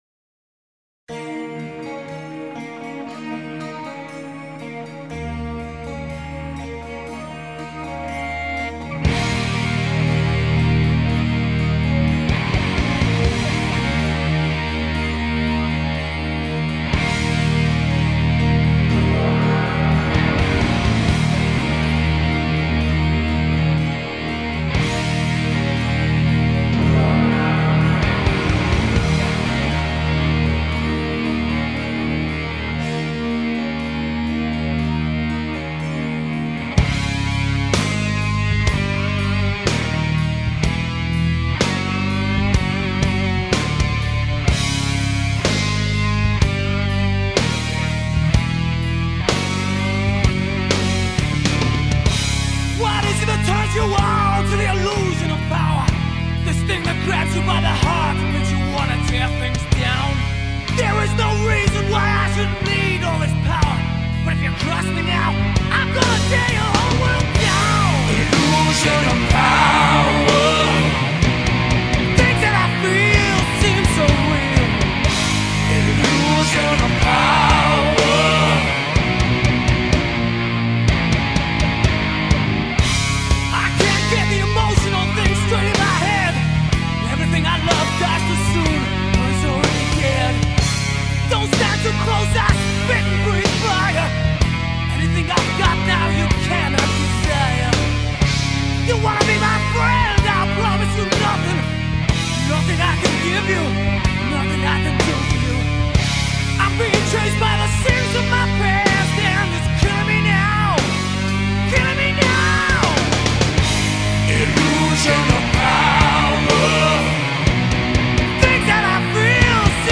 Heavy Metal, Hard Rock